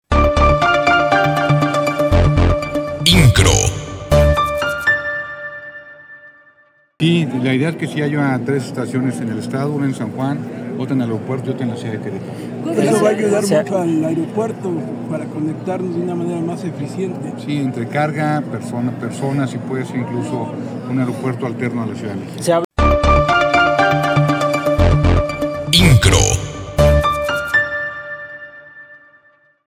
Mauricio Kuri González, gobernador del estado, adelantó que el Gobierno Federal ya trabaja en determinar los lugares en dónde se podrían instalar las estaciones para el Tren México-Querétaro.